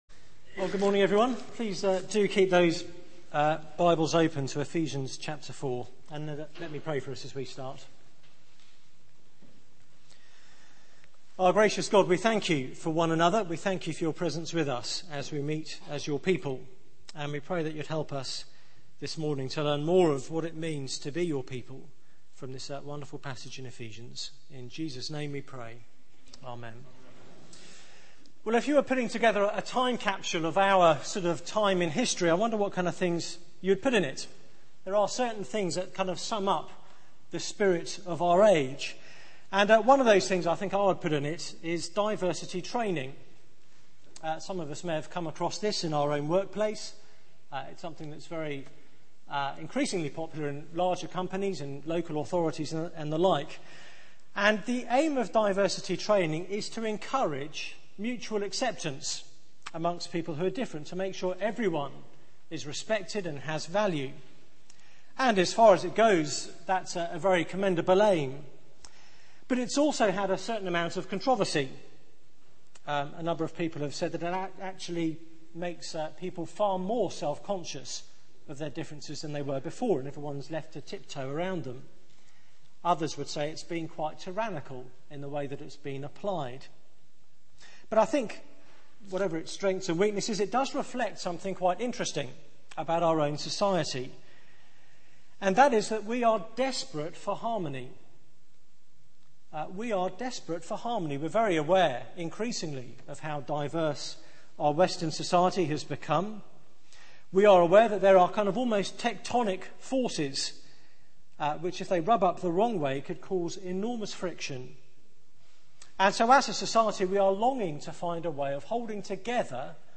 Media for 9:15am Service on Sun 13th Jun 2010 09:15 Speaker
Theme: 'Unity and Diversity' Sermon